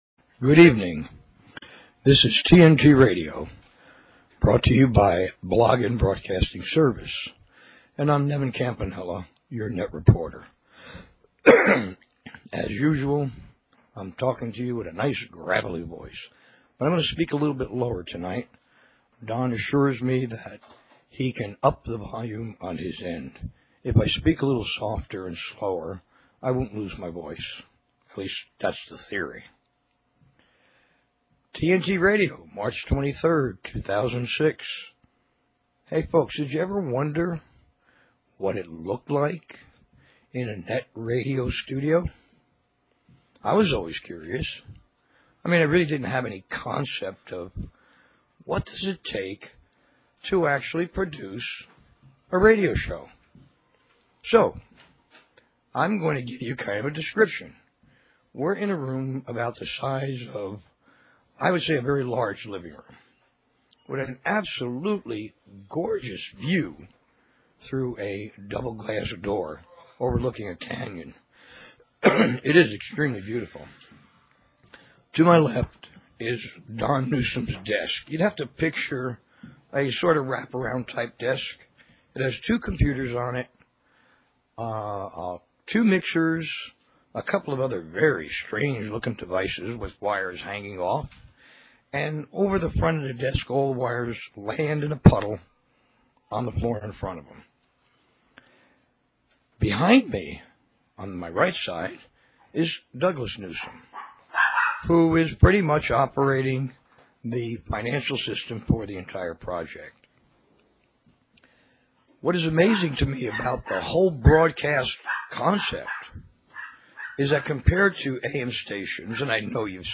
Talk Show Episode, Audio Podcast, TNT_Radio and Courtesy of BBS Radio on , show guests , about , categorized as